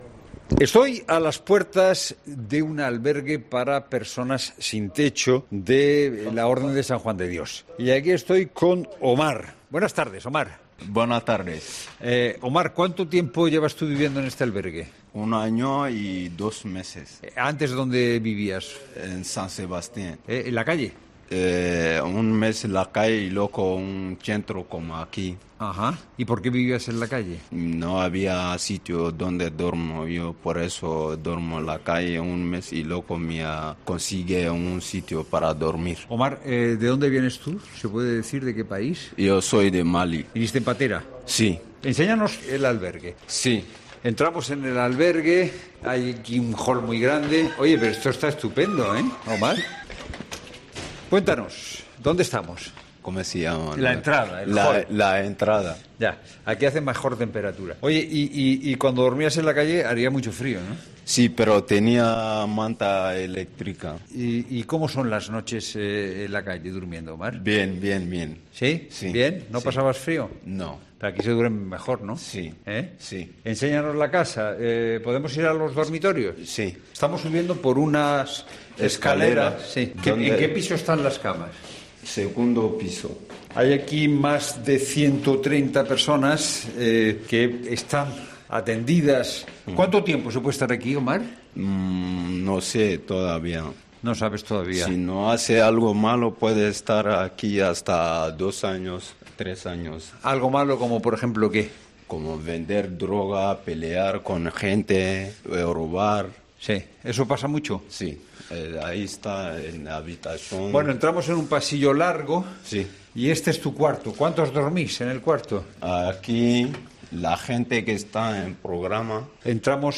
Fernando de Haro visita el albergue San Juan de Dios, en Madrid, para conocer la situación de los que viven actualmente allí